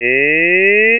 Glissando
Glissando is shown using the ee sound in Feed. Using the linseg command in CSound, a ramp is formed which raises the pitch from a lower to an upper level, creating glissando.
eeGliss.wav